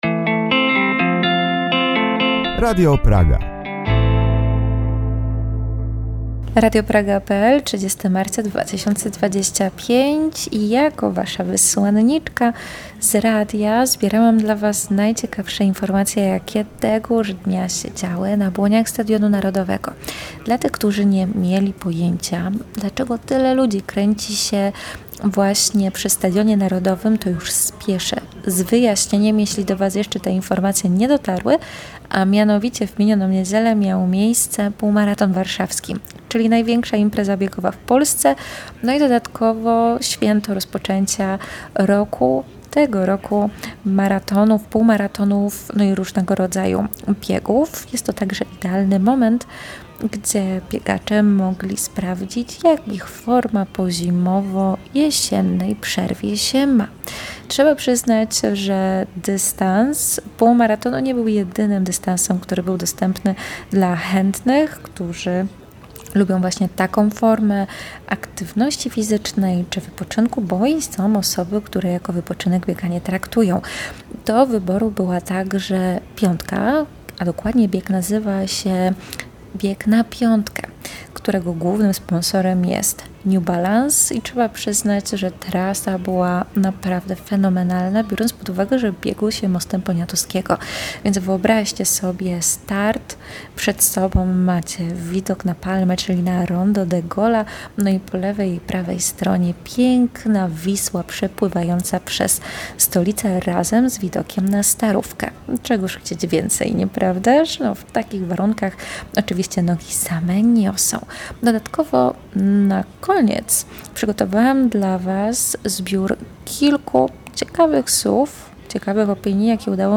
W relacji usłyszycie nie tylko emocjonujące momenty z biegu, ale także rozmowy z uczestnikami oraz kilka słów